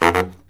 LOHITSAX08-R.wav